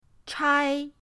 chai1.mp3